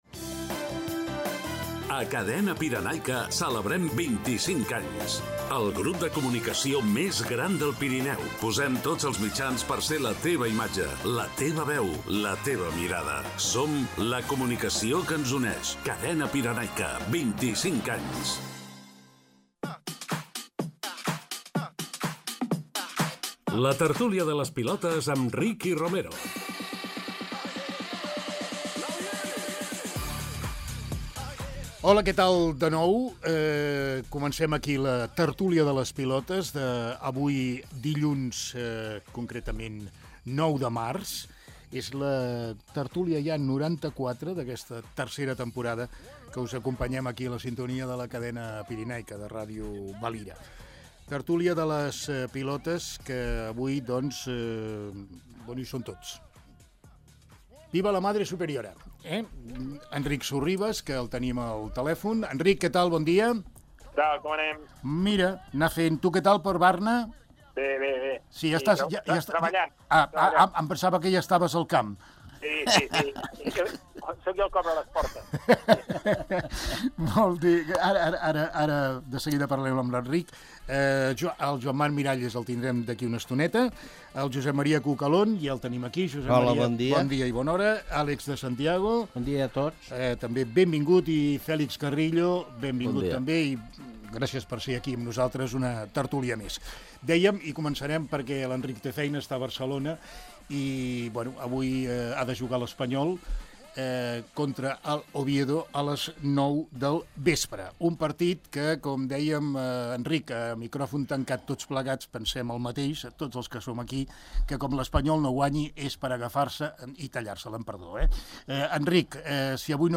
LA TERTÚLIA